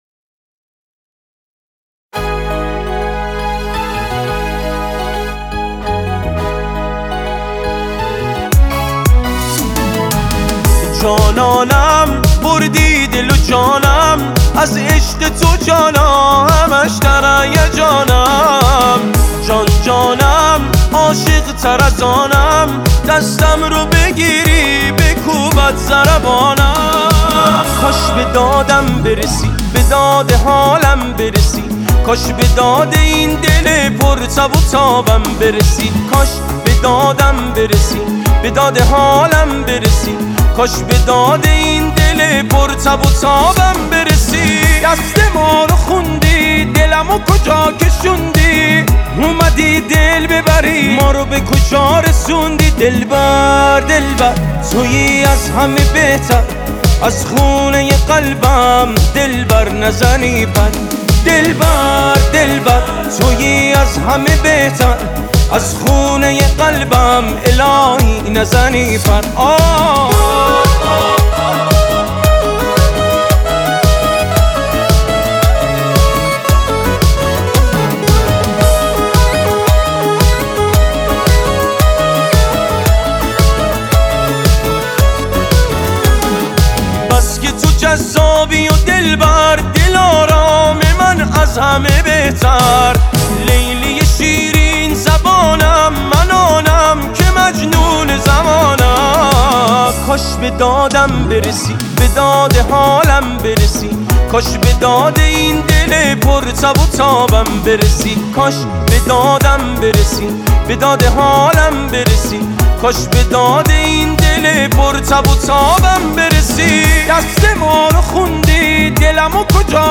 دانلود اهنگ جدید و شاد و خیلی خوب